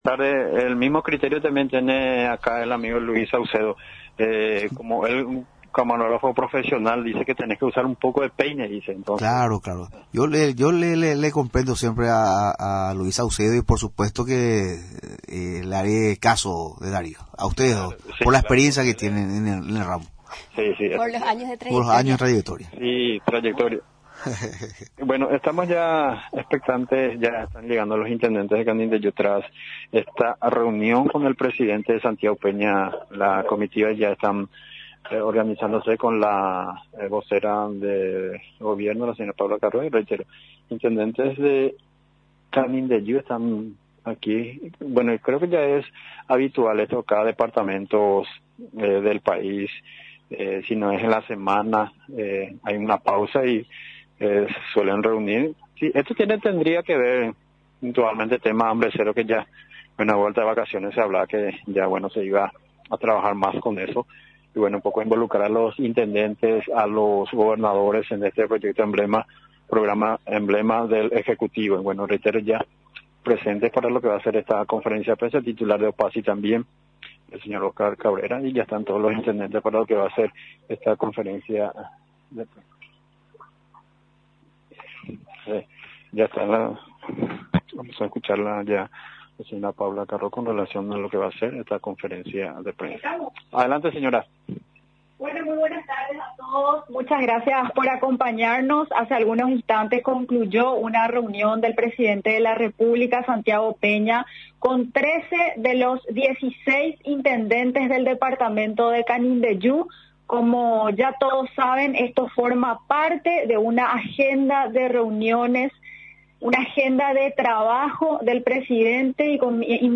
El intendente de San Isidro Labrador del Curuguaty, Walter Pío Ramírez Chaparro, destacó durante la rueda de prensa, realizada en la Residencia Presidencial de Mburuvichá Róga, la importancia de este encuentro, calificándolo de histórico.